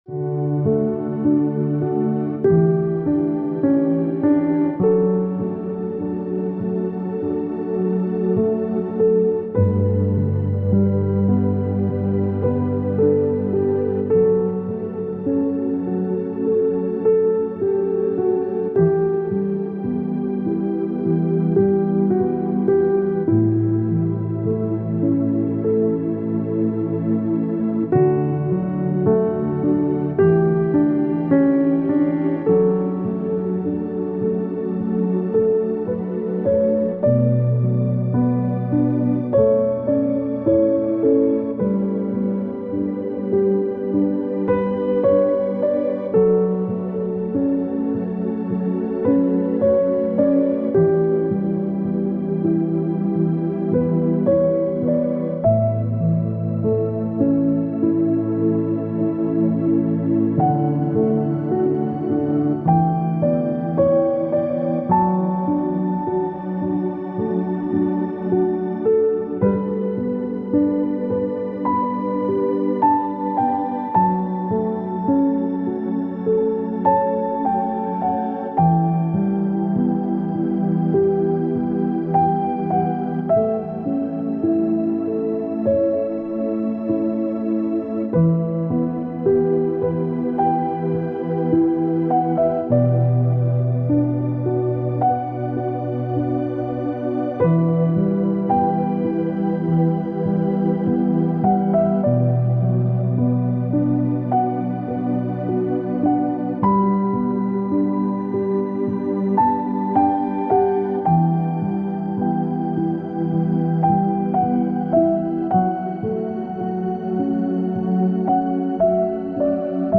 Leichter Regen-Vogelfrieden: Stimmen voller entspannender Ruhe